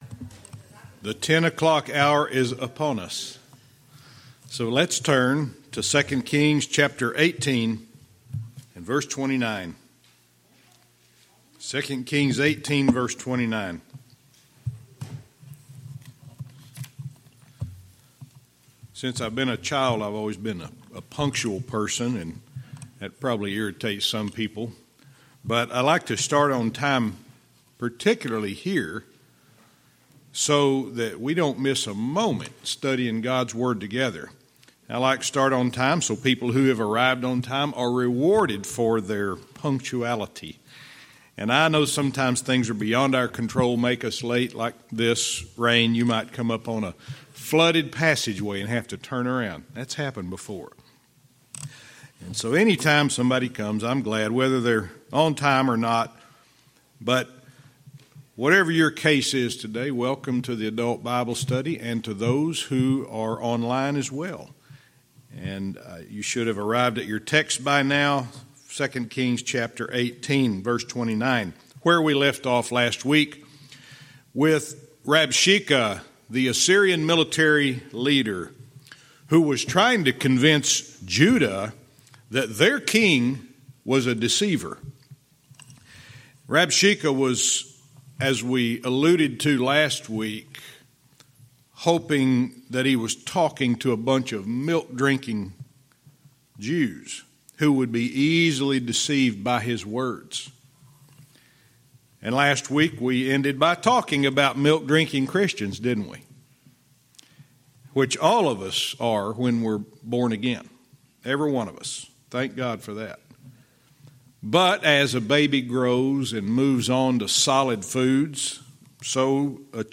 Verse by verse teaching - 2 Kings 18:29(cont)